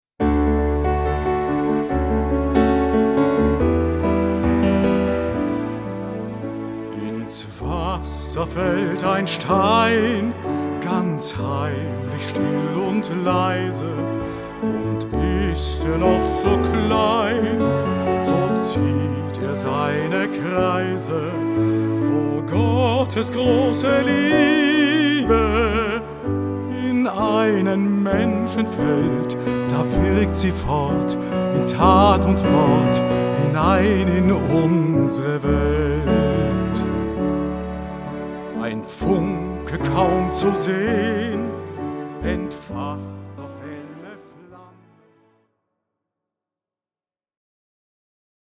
Live-Mitschnitte: